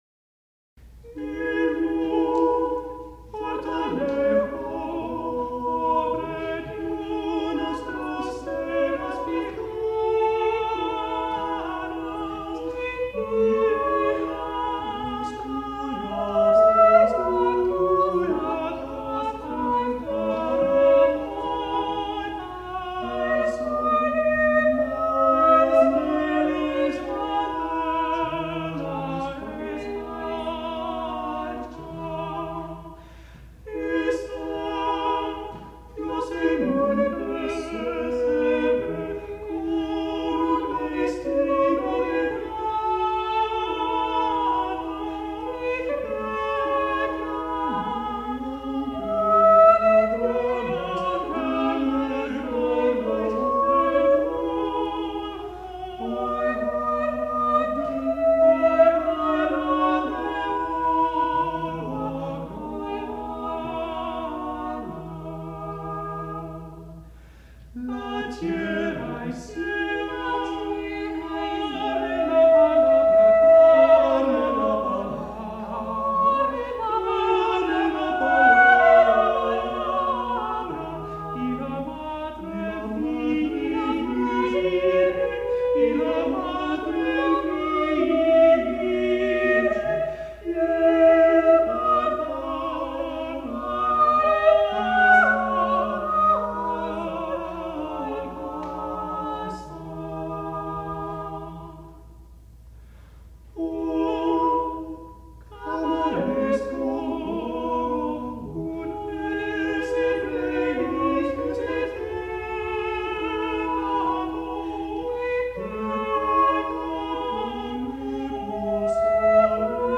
Many of the pieces are lively spiritual villancicos written for Christmas and Corpus Christi, which were especially requested by the authorities and much appreciated by the populace.